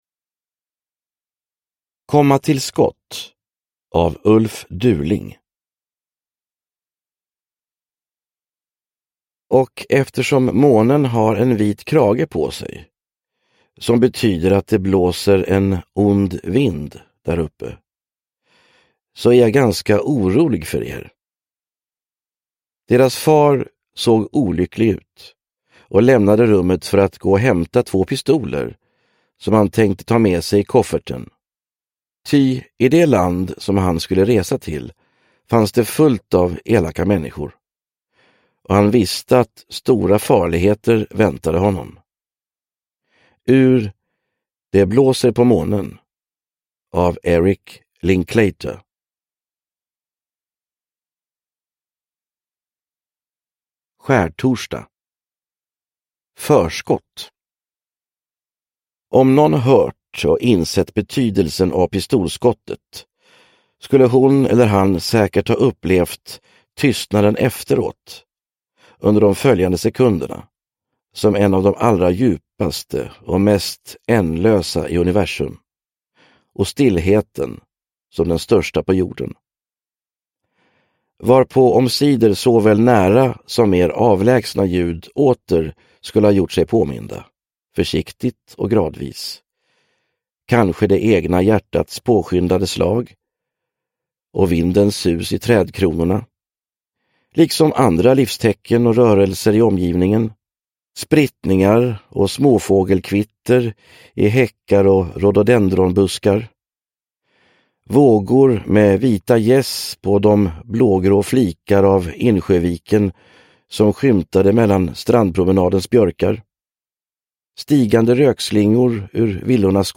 Komma till skott – Ljudbok